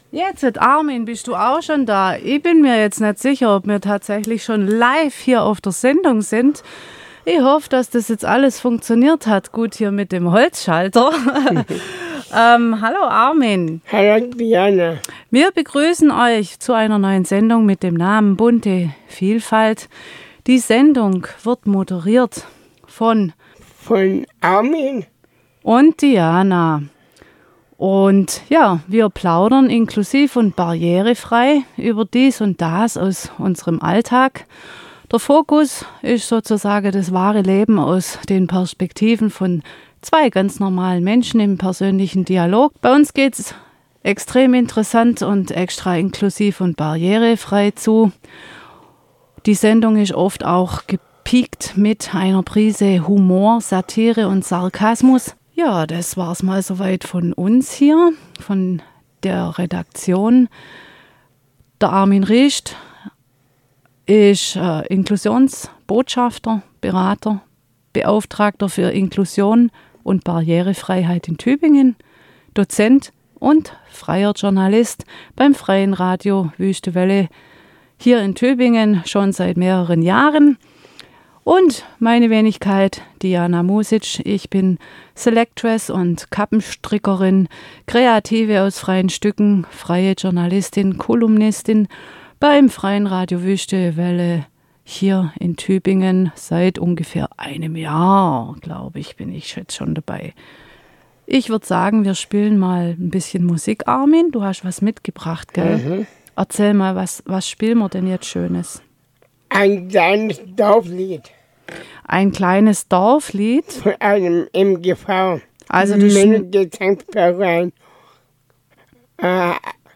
Es geht ab und an nicht nur kunterbunt zu, was die Themen betrifft, sondern auch die Emotionen. Durch die verschiedenen Sichtweisen der beiden Sendungsmachenden, wie auch der unterschiedlichen Lebenserfahrungen, ergeben sich spontane Dialoge.